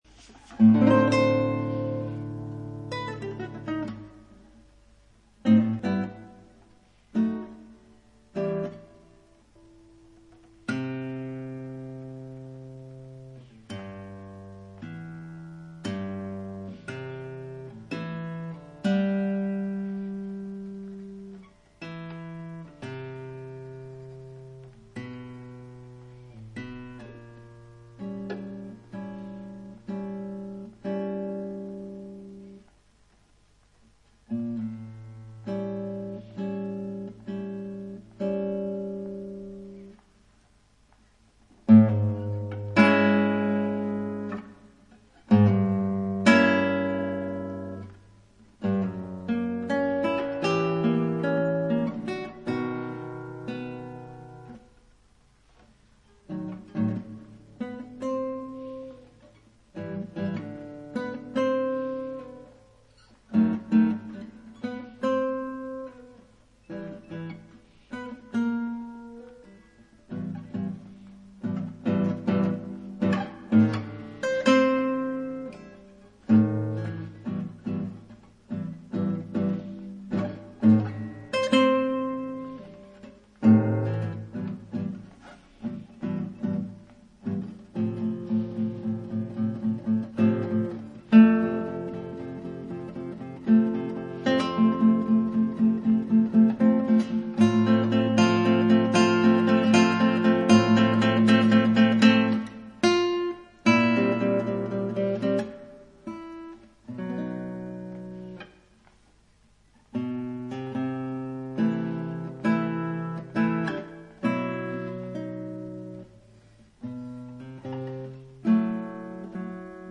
J'ai fait ça d'une traite, alors excusez les queues de notes à l'envers, mais content d'être arrivé au bout et je crois pour la dernière fois que je la joue en entier et par coeur.
Ma corde de ré m'a joué des tours : trop sonore et l'accord a un peu bougé, mais bof ...